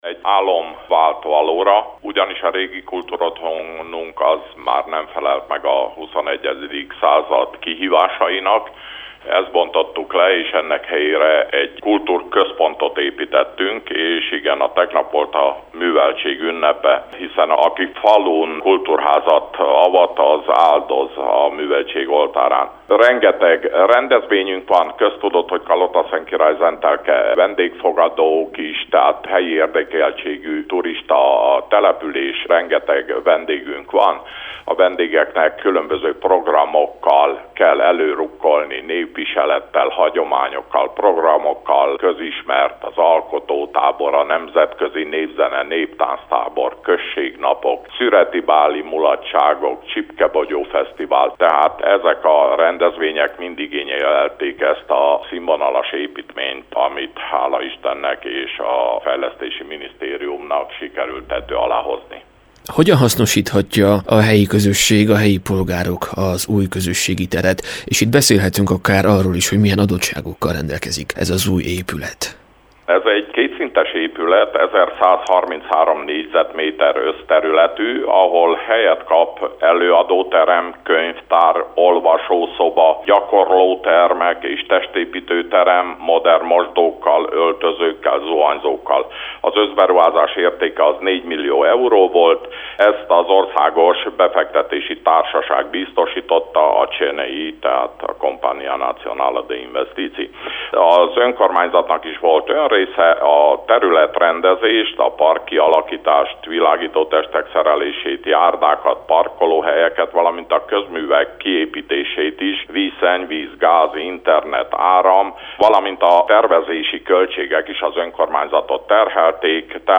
Az új közösségi tér szerepéről Póka András György polgármestert kérdezte